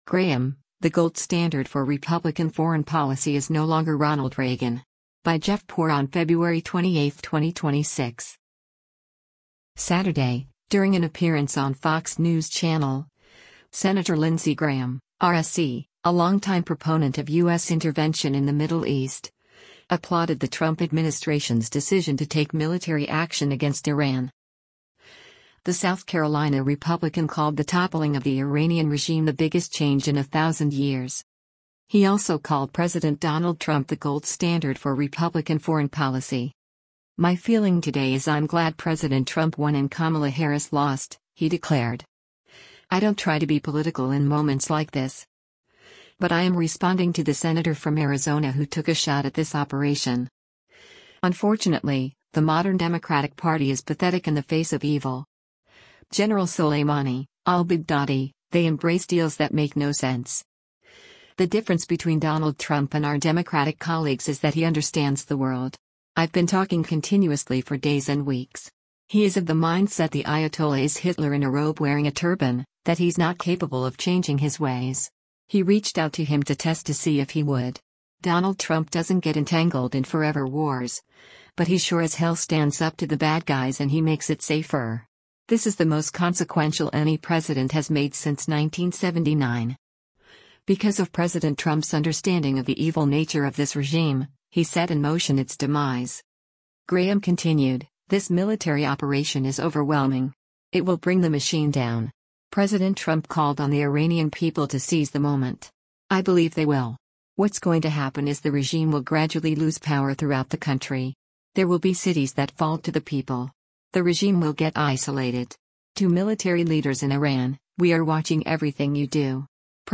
Saturday, during an appearance on Fox News Channel, Sen. Lindsey Graham (R-SC), a longtime proponent of U.S. intervention in the Middle East, applauded the Trump administration’s decision to take military action against Iran.